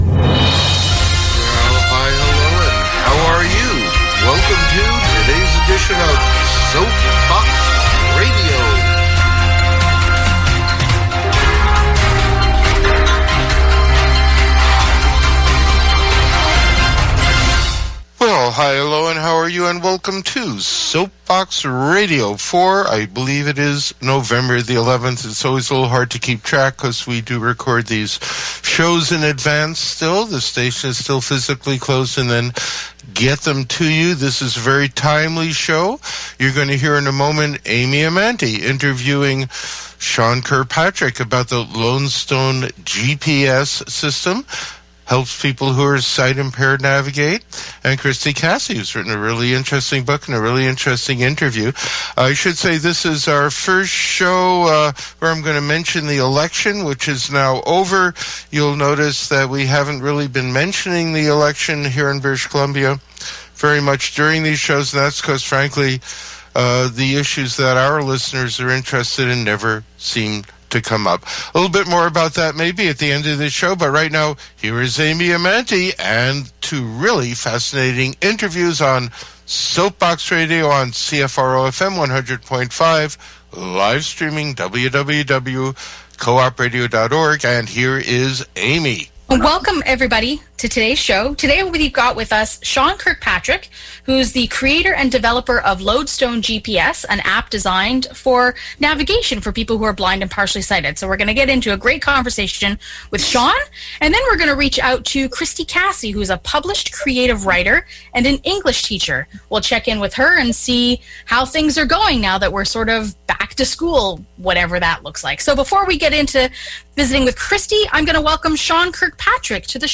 The interview aired on November 11, 2020. Listen to the Soapbox Radio interview.